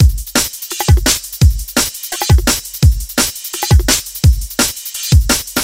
Drum And Bass Loop 06 170BPM
Tag: 170 bpm Drum And Bass Loops Drum Loops 972.84 KB wav Key : Unknown